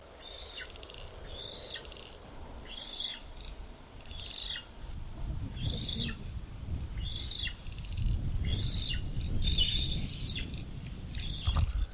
It turned out to be an Australian White Ibis nest, with 2 adults and a pesky youngster, apparently close to fledging.
The chick was certainly insistent in its calling, as can be heard by clicking on the icon below.
ibis-chicks.mp3